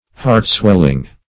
Meaning of heartswelling. heartswelling synonyms, pronunciation, spelling and more from Free Dictionary.
Search Result for " heartswelling" : The Collaborative International Dictionary of English v.0.48: Heartswelling \Heart"swell`ing\ (h[aum]rt"sw[e^]l`[i^]ng), a. Rankling in, or swelling, the heart.
heartswelling.mp3